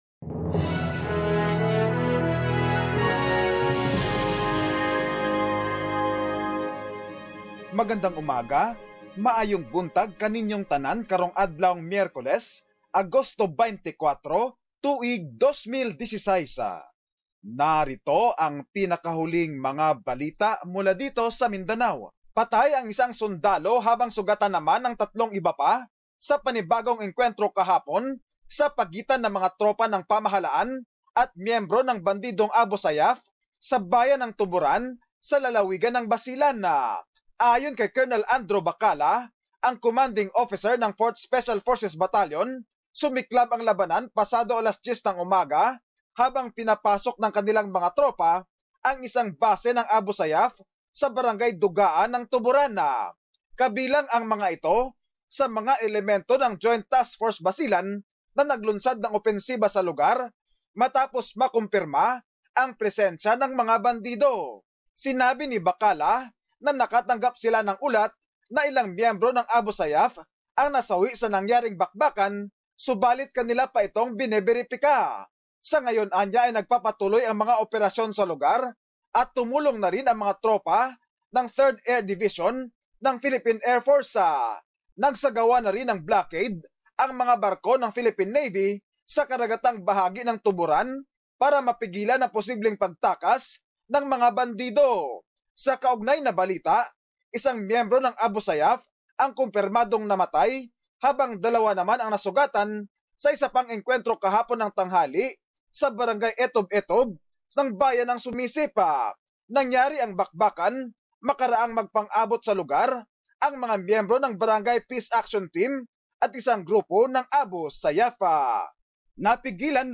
Mindanao news.